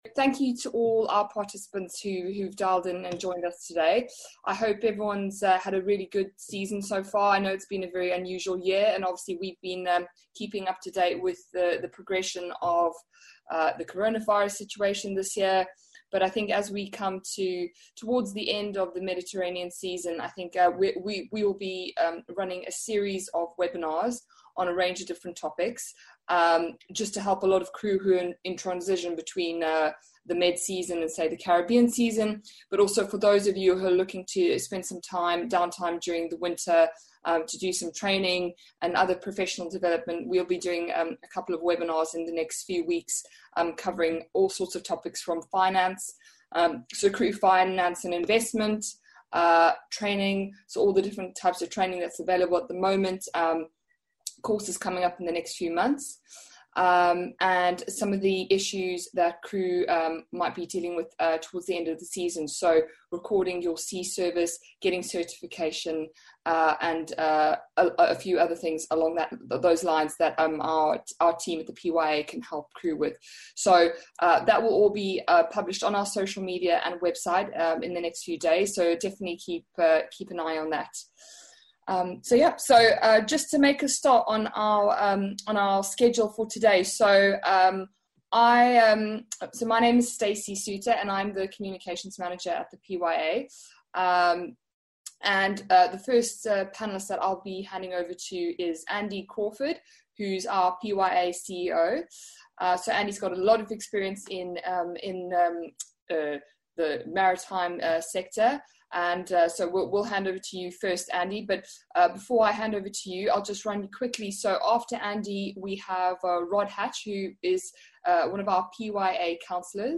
PYA - Webinar : Conflict Resolution & Leadership